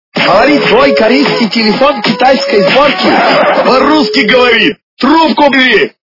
» Звуки » Люди фразы » Голос - Говорит корейский телефон китайской сборки
При прослушивании Голос - Говорит корейский телефон китайской сборки качество понижено и присутствуют гудки.